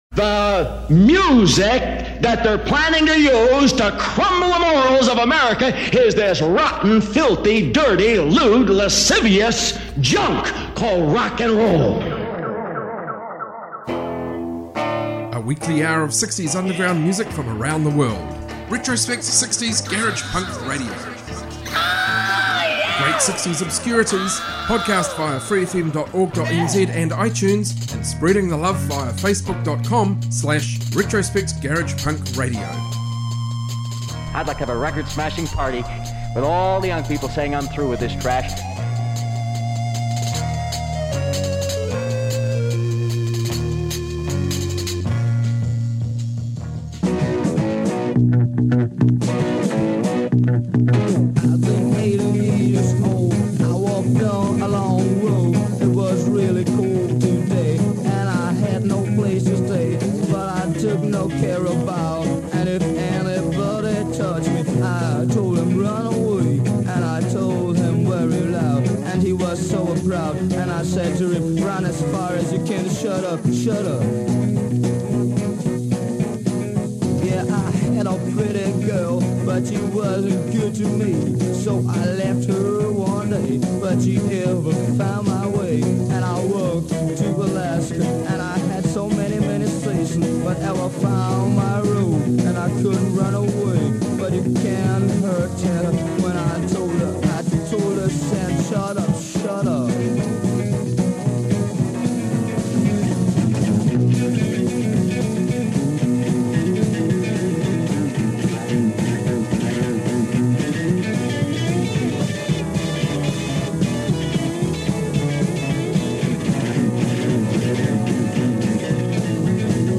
global garage rock garage punk proto-punk freakbeat